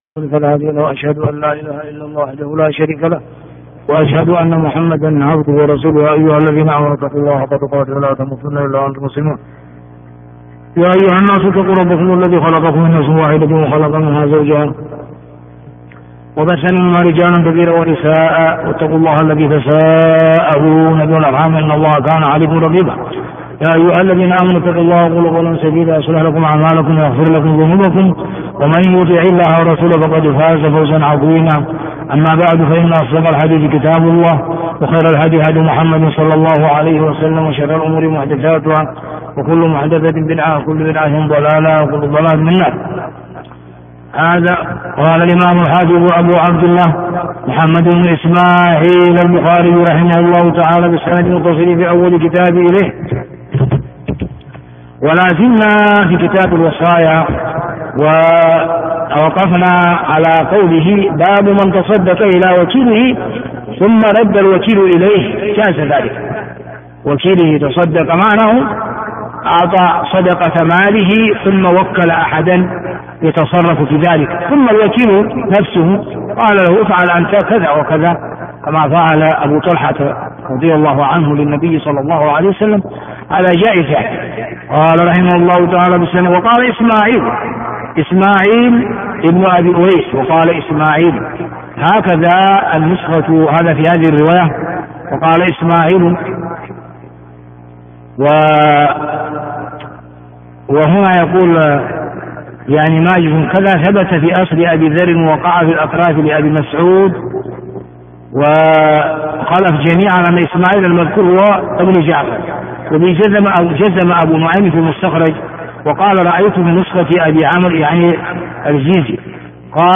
الدرس 190 - كتاب الصلح - بَابُ مَنْ تَصَدَّقَ إِلَى وَكِيلِهِ ثُمَّ رَدَّ الوَكِيلُ إِلَيْهِ - ح 2758